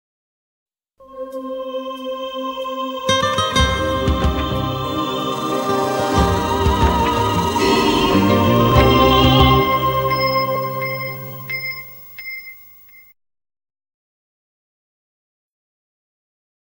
un mix du l'original et de la version espagnole
Stéréo